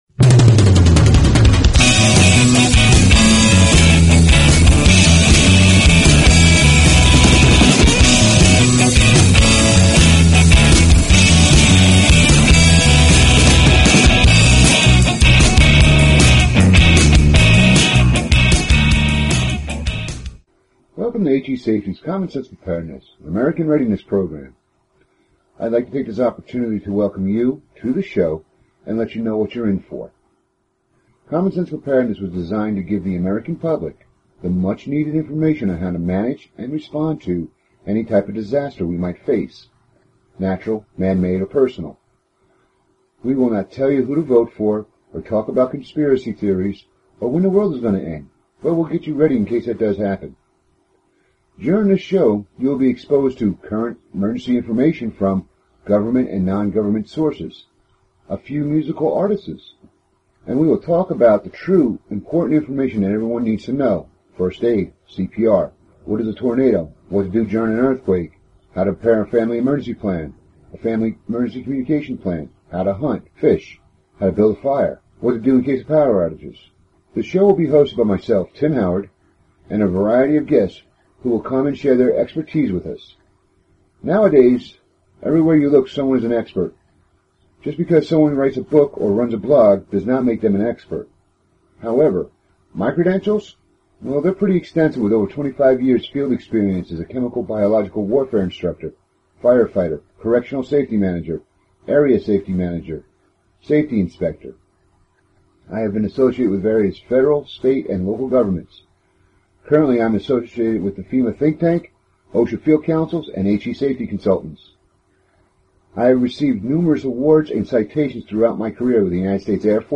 Talk Show Episode, Audio Podcast, Common_Sense_Preparedness and Courtesy of BBS Radio on , show guests , about , categorized as